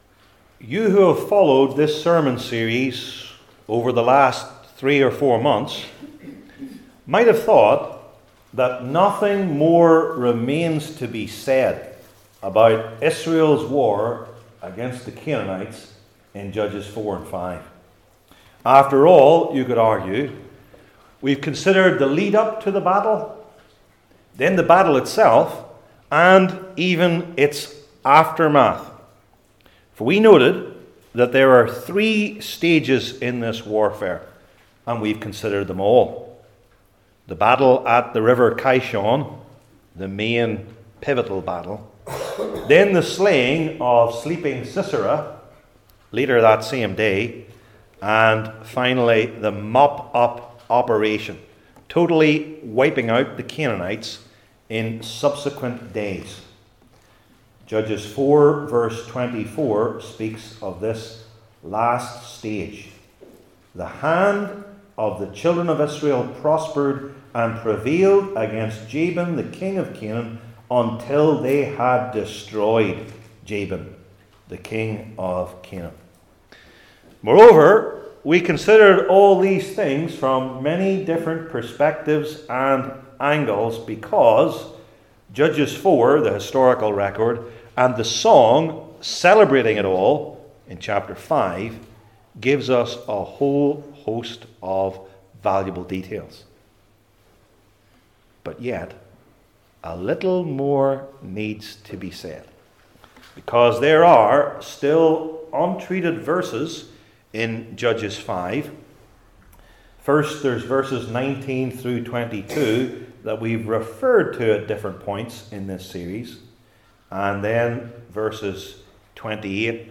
28-31 Service Type: Old Testament Sermon Series I. The Additional Warriors II.